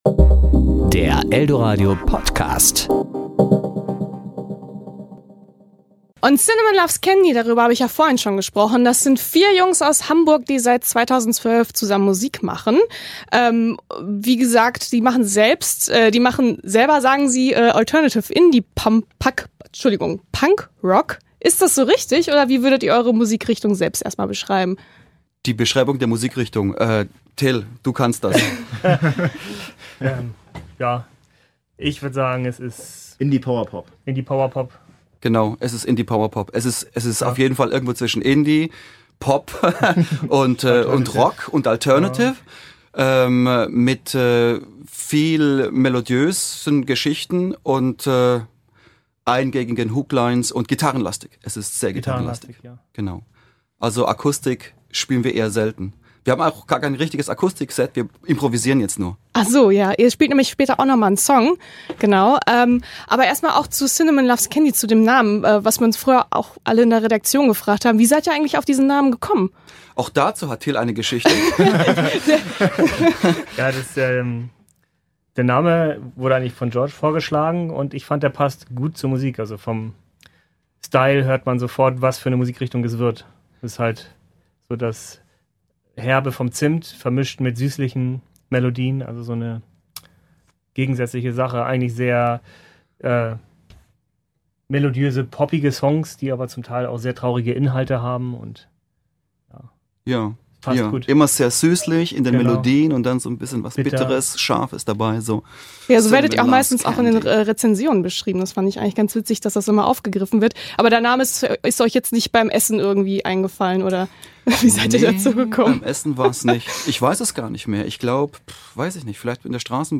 Serie: Interview